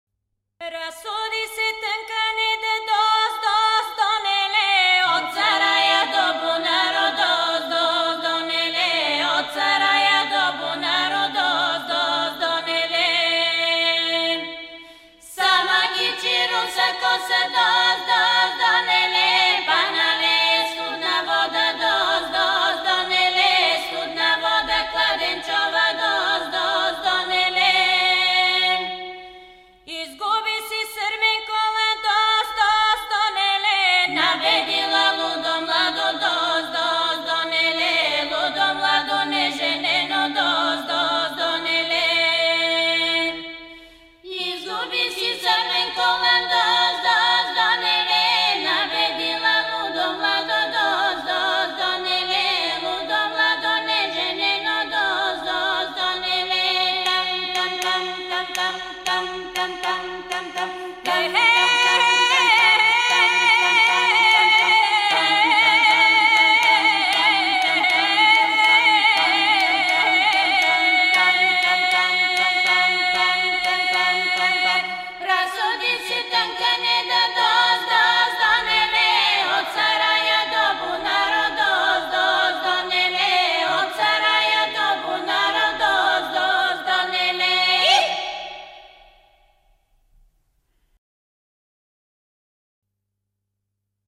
A Cappella - Polyphonies vocales : Gênes et Fribourg
Bulgaria Slavei Voix de femmes bulgares
soprano
mezzo soprano
contre-alto
bulgarie.mp3